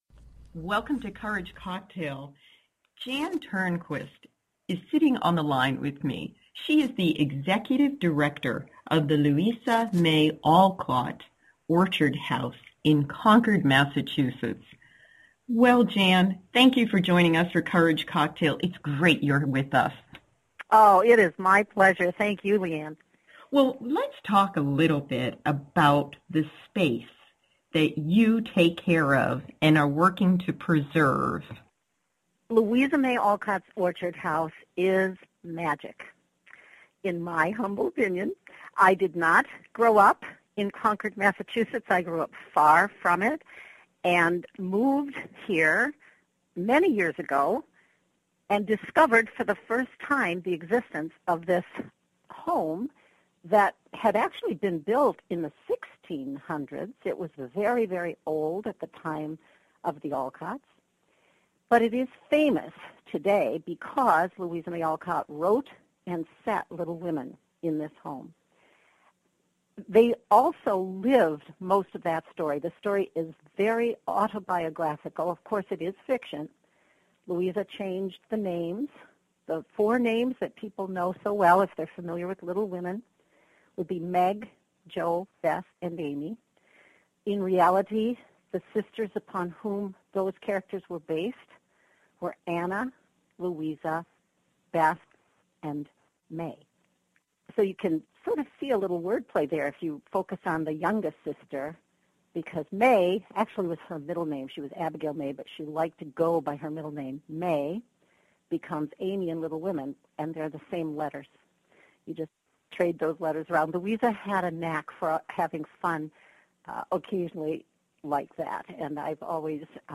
Beginning today: 4-part interview with the movers and shakers of the Orchard House documentary and Kickstarter fundraising campaign
I wish to thank WCOM for permission to rebroadcast this interview.